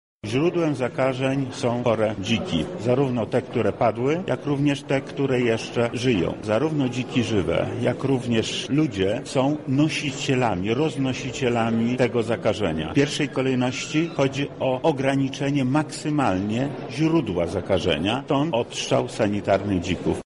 Jestem zwolennikiem radykalnych rozwiązań, jeśli chodzi o odstrzał dzików– mówi Wojewoda Lubelski Lech Sprawka: